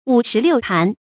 五石六鹢 注音： ㄨˇ ㄉㄢˋ ㄌㄧㄨˋ ㄧˋ 讀音讀法： 意思解釋： 語出《公羊傳·僖公十六年》：「霣石于宋五。